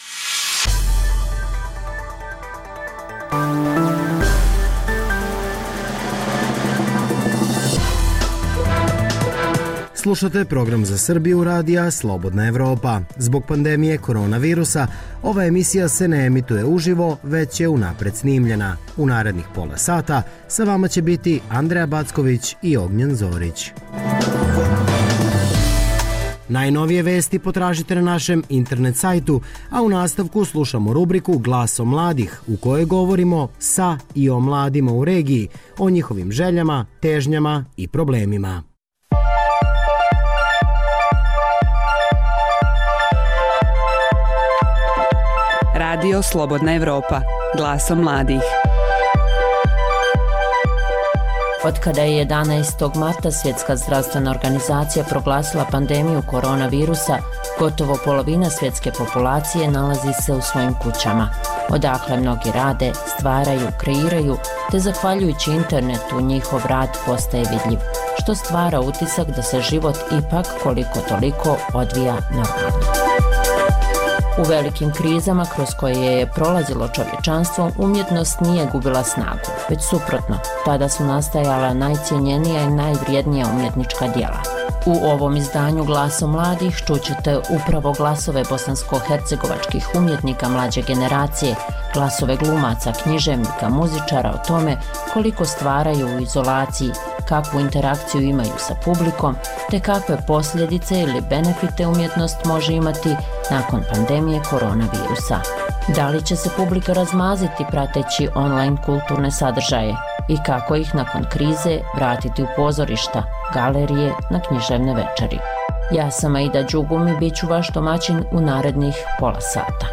Ovo je program Radija Slobodna Evropa za Srbiju. Zbog pandemije korona virusa ova emisija je unapred snimljena.